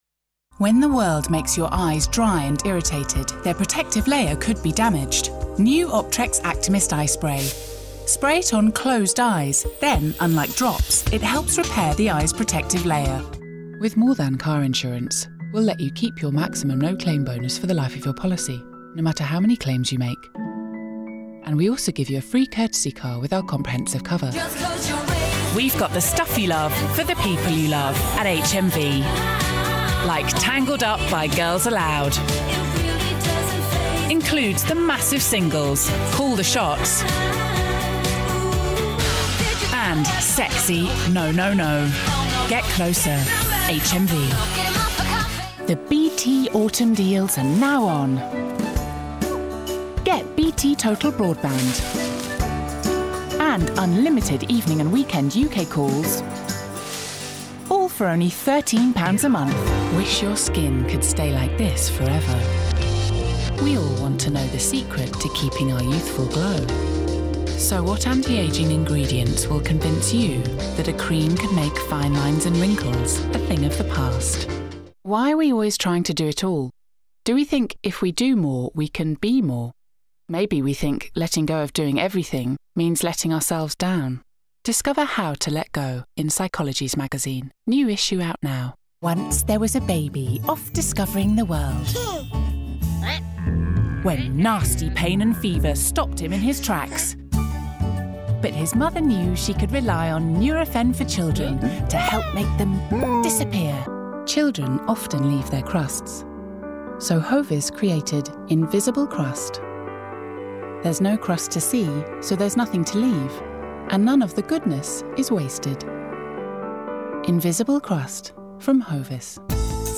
• Female
• Standard English R P